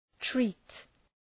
Προφορά
{tri:t}